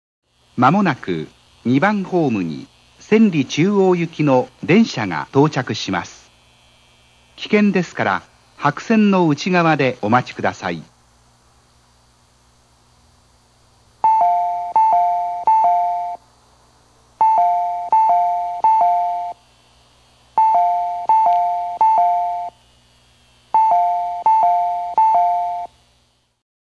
大阪モノレール線旧駅放送